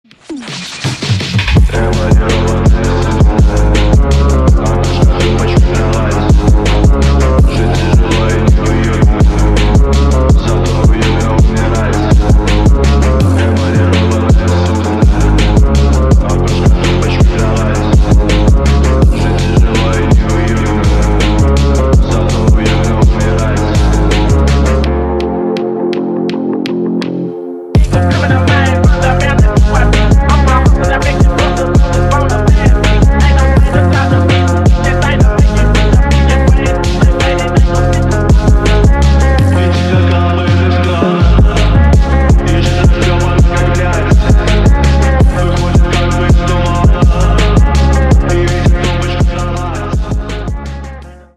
Архив Рингтонов, Рэп рингтоны